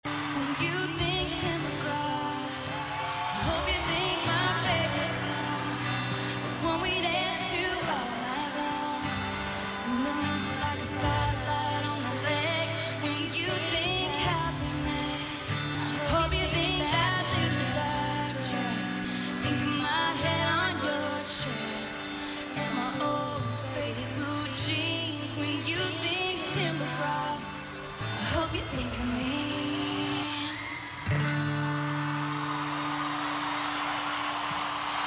Listen with headphones Left ear: 2013Right ear: 2007